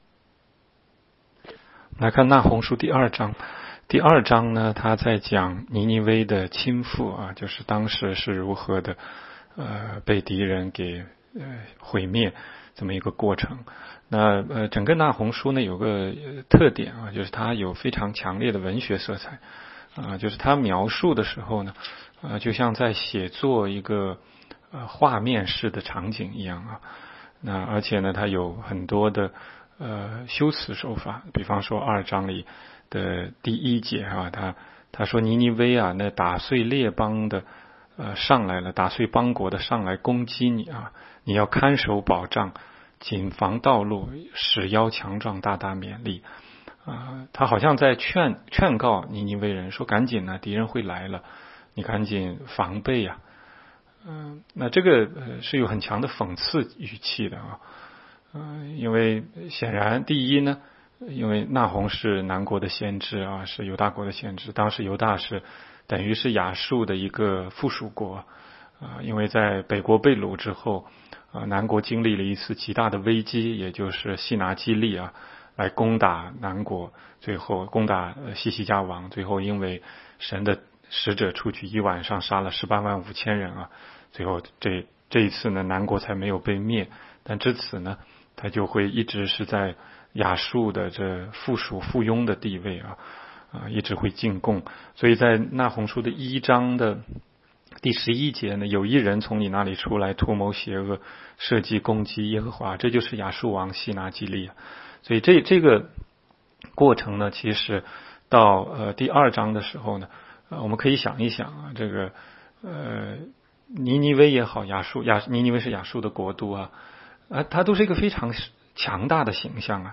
16街讲道录音 - 每日读经 -《那鸿书》2章